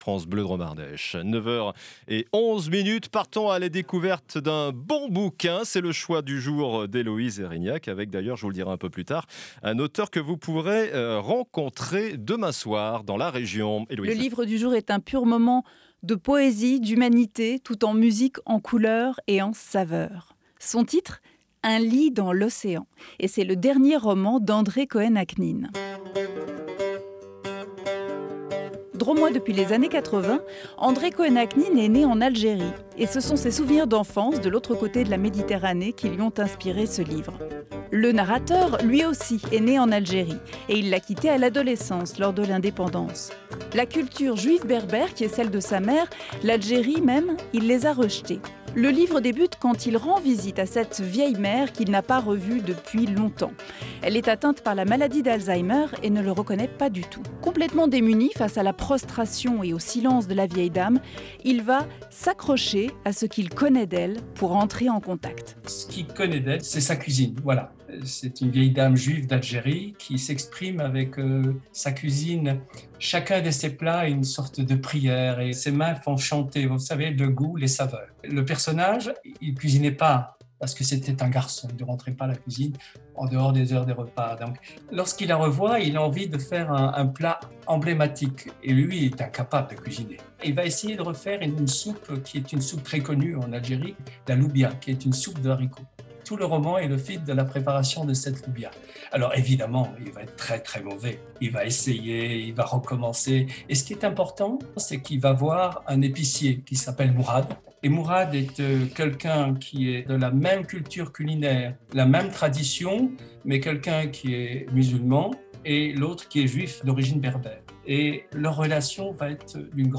Interview
Rubrique « livre » du magazine culture de 9h à 9h30, sur France Bleue Drôme Ardèche, le 10 mars 2022 :